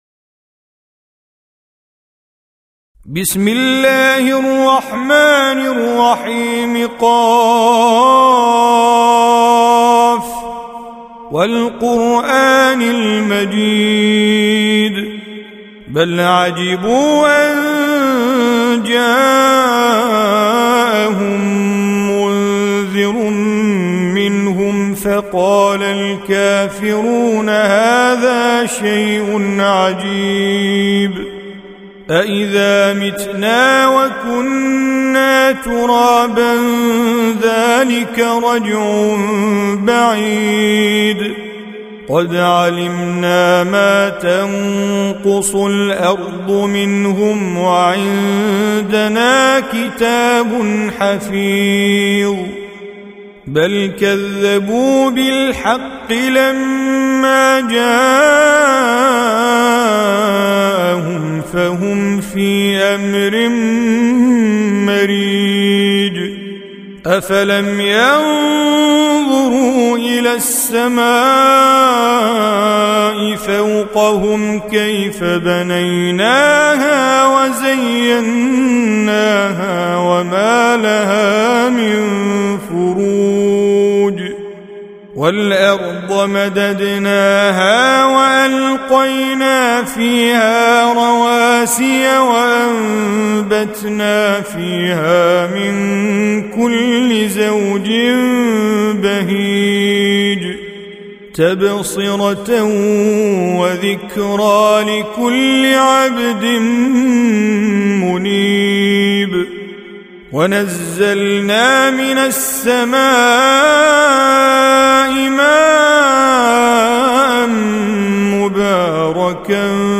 Surah Repeating تكرار السورة Download Surah حمّل السورة Reciting Mujawwadah Audio for 50. Surah Q�f. سورة ق N.B *Surah Includes Al-Basmalah Reciters Sequents تتابع التلاوات Reciters Repeats تكرار التلاوات